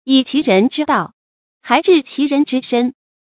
成語拼音yǐ qí rén zhī dào，huán zhì qí rén zhī shēn
發音讀音
成語正音還，不能讀作“hái”。